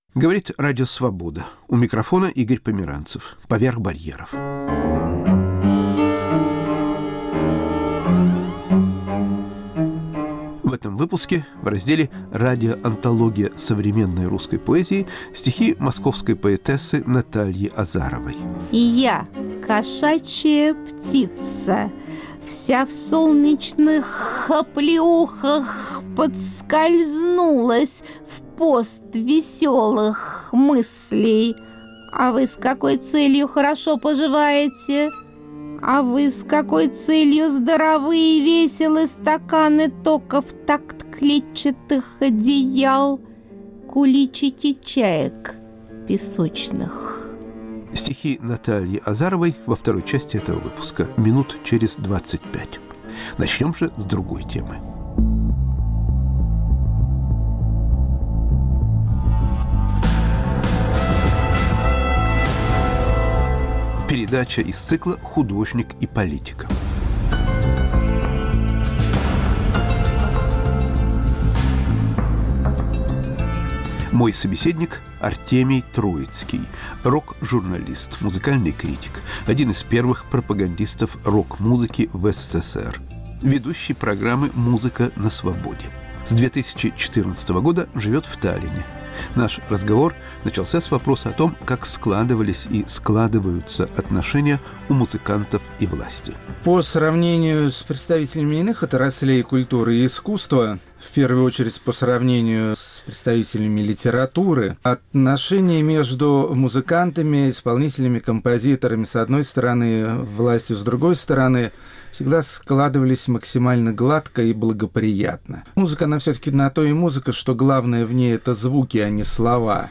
Разговор с Артемием Троицким.** Радиоантология современной русской поэзии.